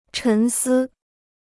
沉思 (chén sī): nachdenken; sinnieren.